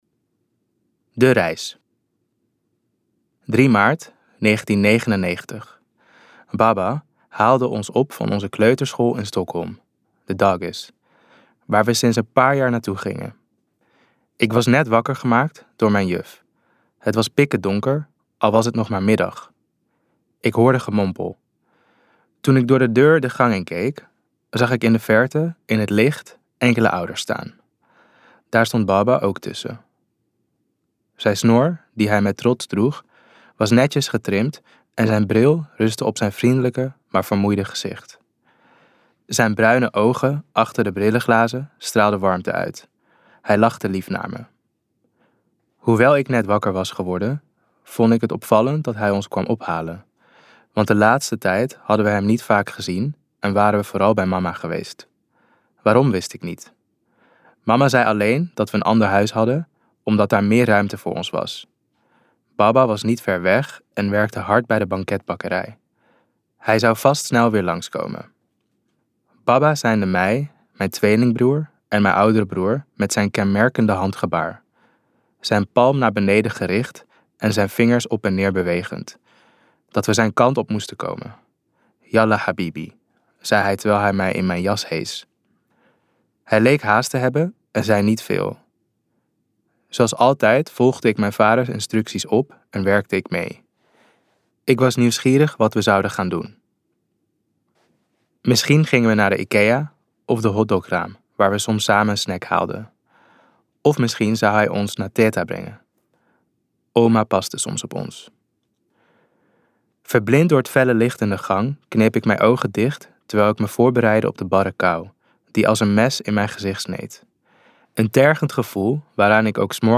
Baba luisterboek | Ambo|Anthos Uitgevers